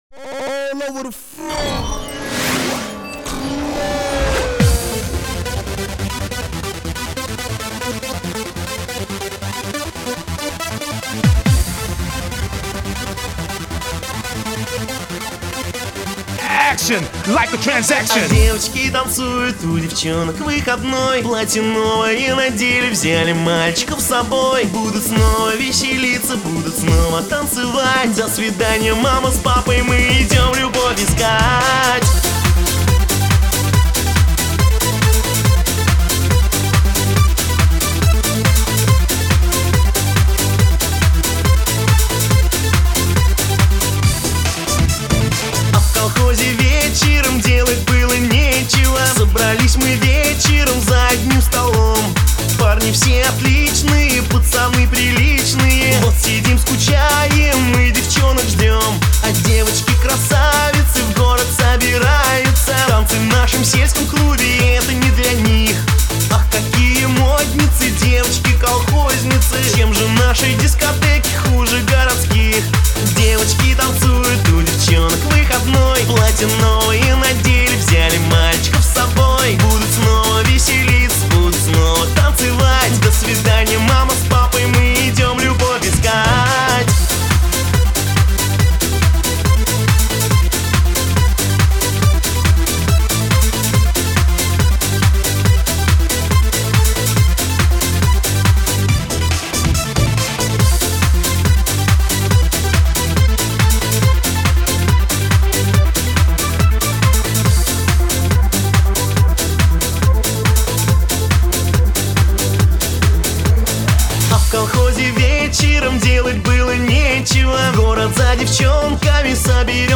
Жанр: Ретро, размер 7.17 Mb.